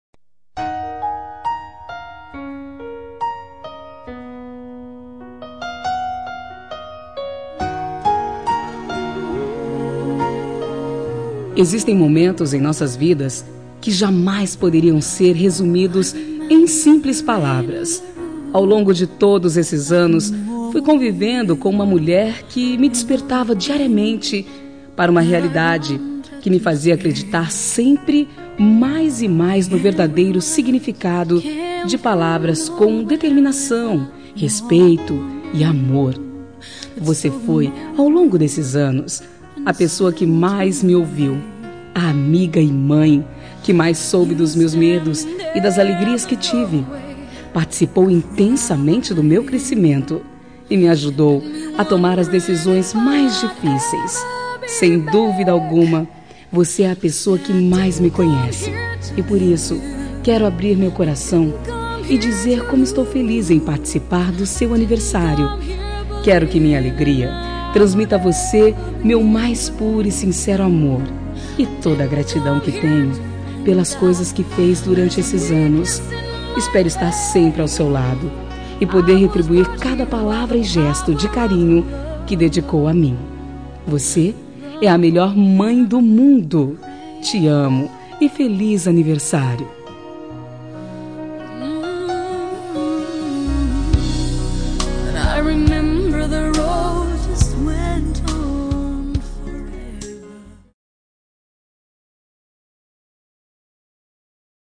Telemensagem Aniversário de Mãe – Voz Feminina – Cód: 1400 Linda